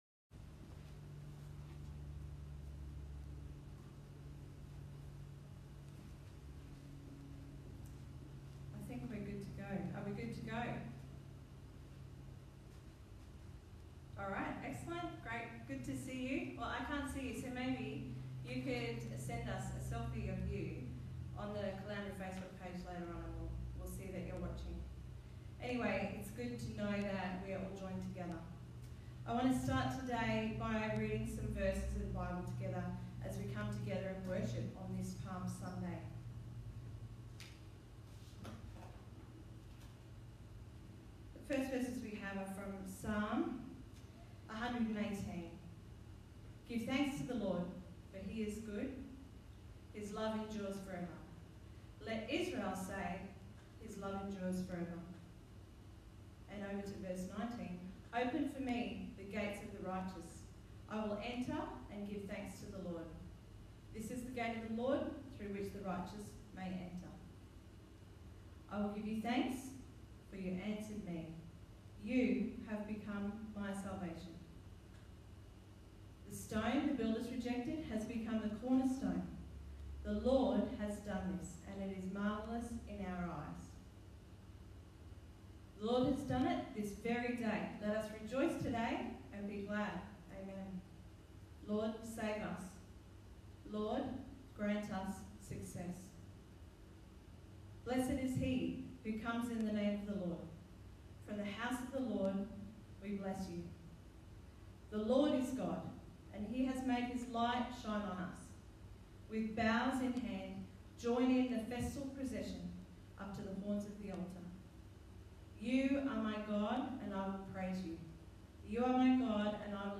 You will need to turn up the volume.